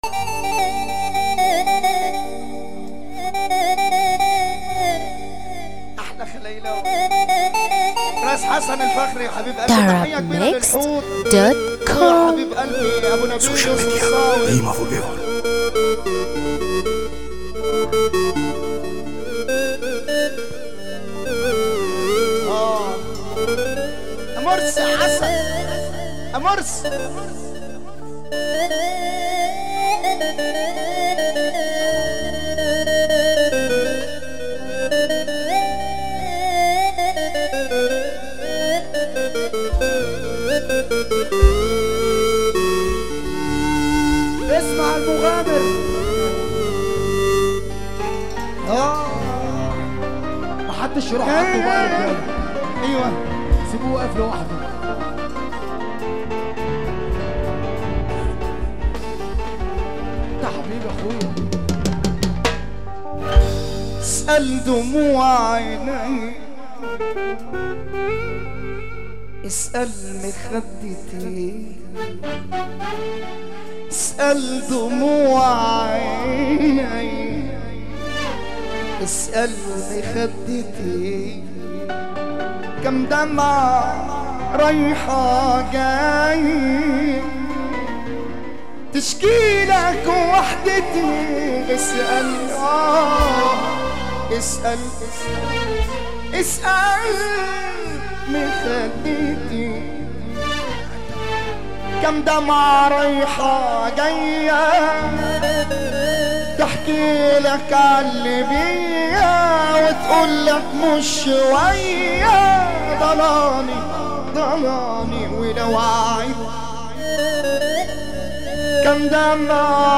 موال
حزين موت